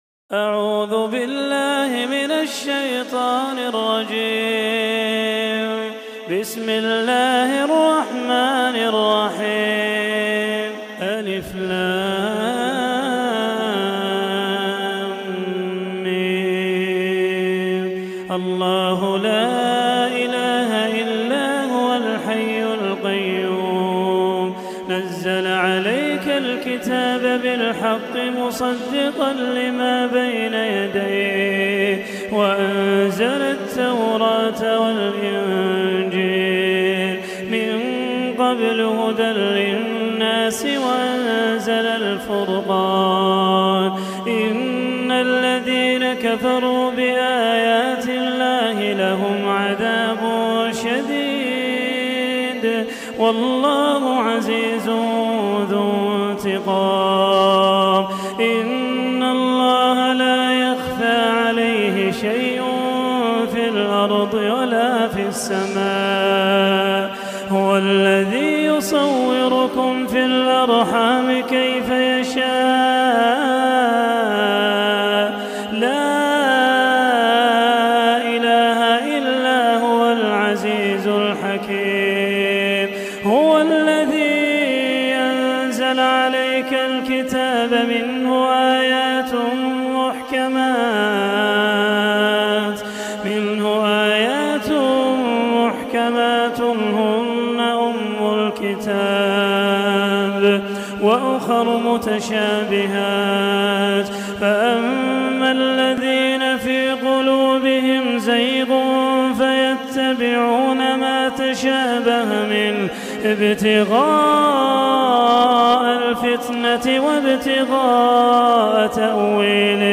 Quran Tilawat